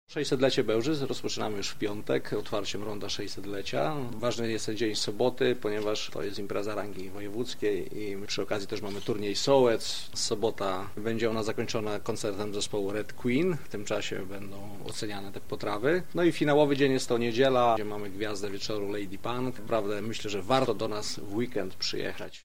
O programie konkursu który realizowany jest w ramach jubileuszu 600-lecia Bełżyc, mówi wicemarszałek Grzegorz Kapusta: